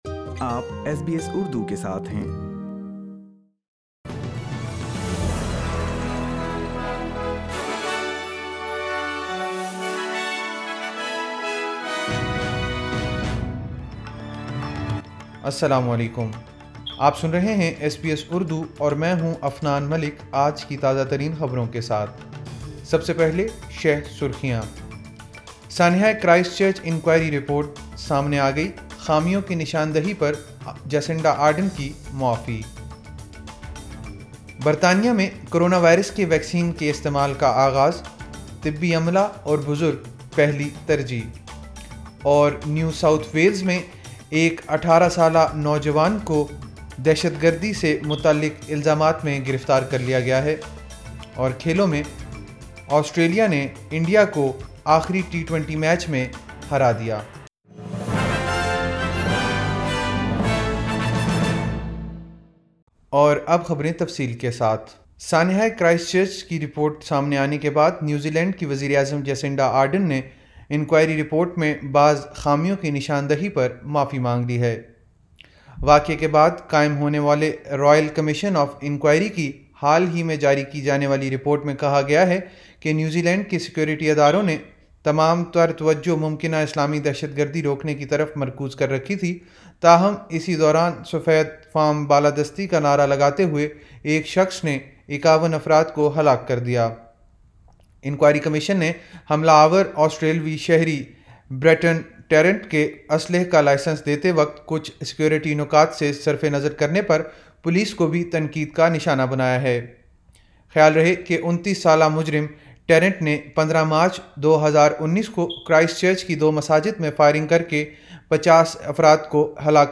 ایس بی ایس اردو خبریں 09 دسمبر 2020